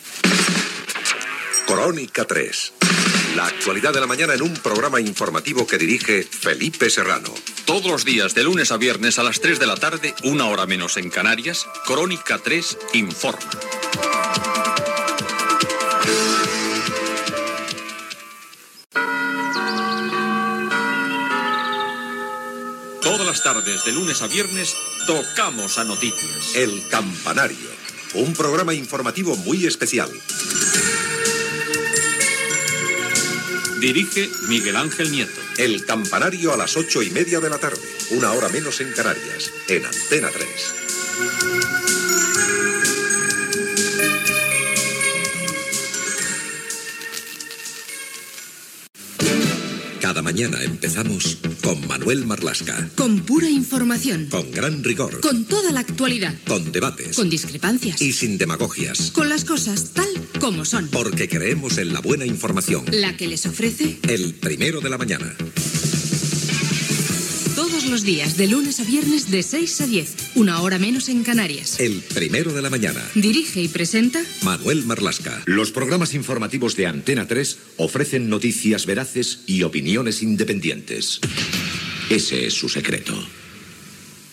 Promocions dels programes "Crónica 3", "El campanario", "El primero de la mañana"